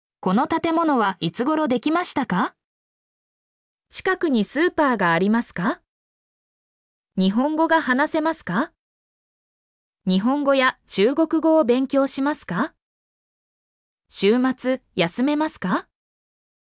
mp3-output-ttsfreedotcom-2_b7C5iayb.mp3